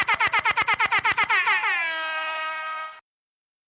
bws-buzzer_24869.mp3